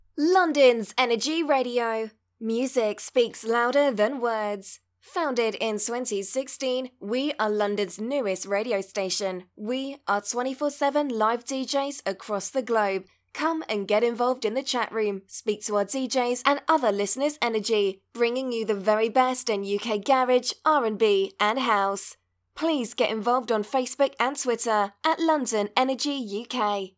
STATION ID LONDONS ENERGY RADIO
Station-ID-7.mp3